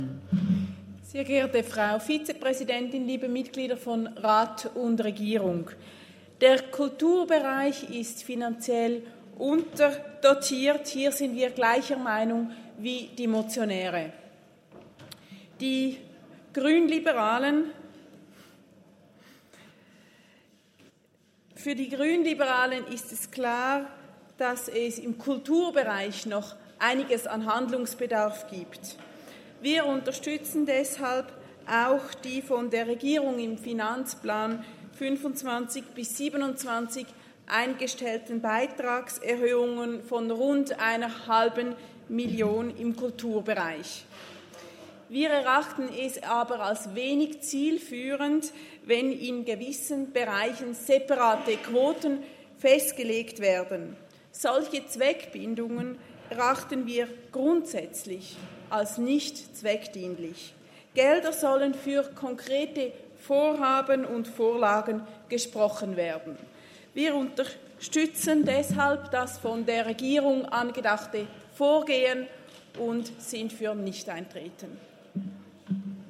Session des Kantonsrates vom 29. April bis 2. Mai 2024, Aufräumsession
1.5.2024Wortmeldung
Lüthi-St.Gallen (im Namen der GLP): Auf die Motion ist nicht einzutreten.